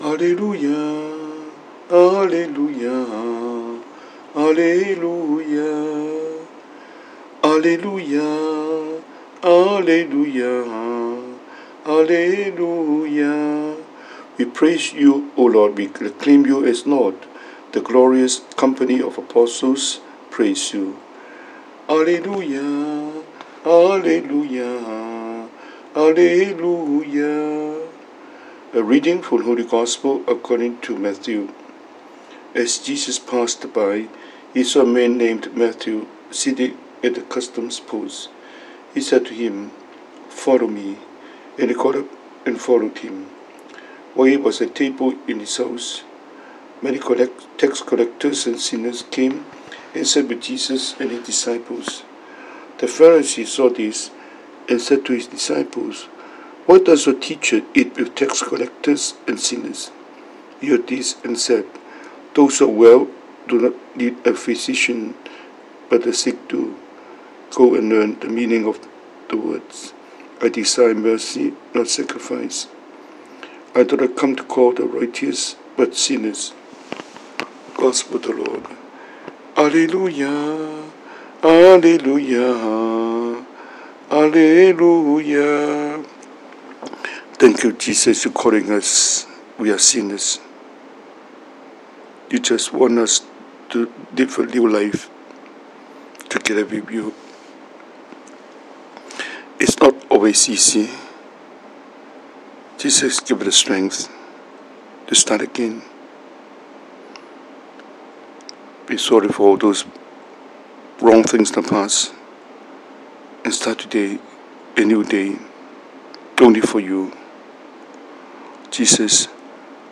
Cantonese Homily,